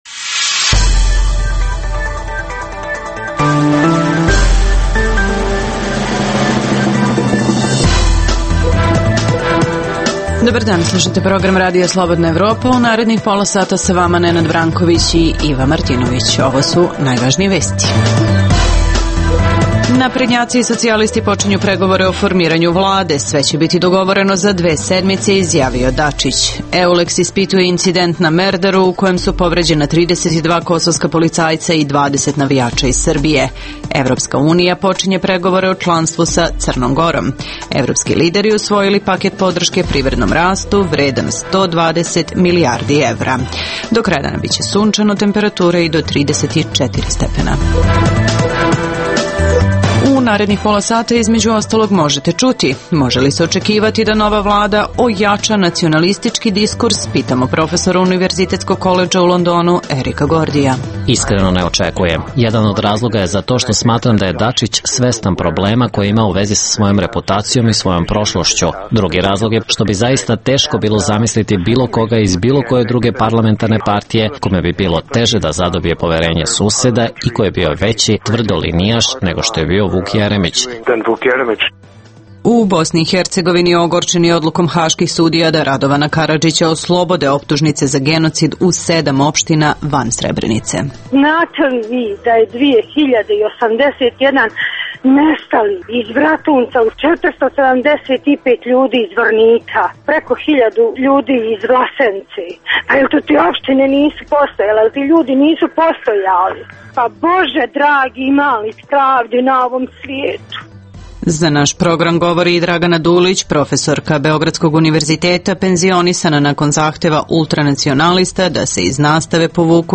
O tome za RSE govore domaći i strani analitičari. - Postoji li opasnost od eskalacije sukoba nakon jučerašnjih incidenata na Merdaru?